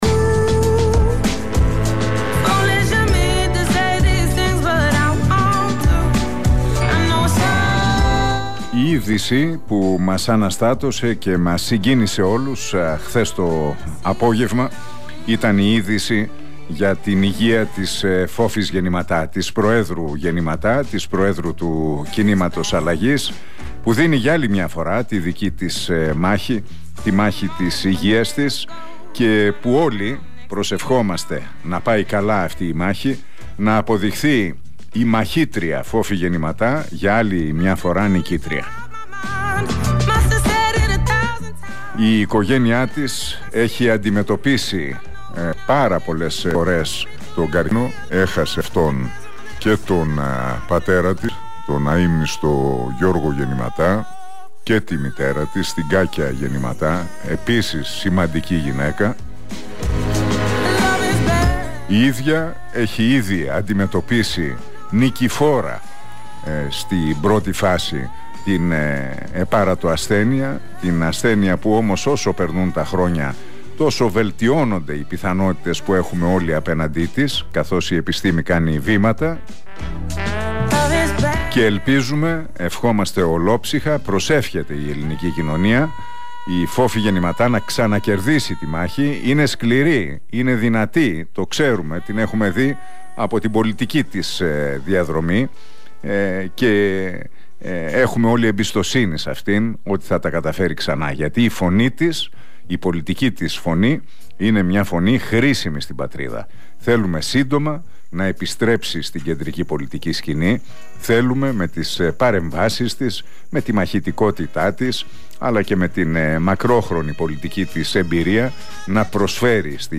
Ακούστε το σημερινό σχόλιο του Νίκου Χατζηνικολάου στον Realfm 97,8.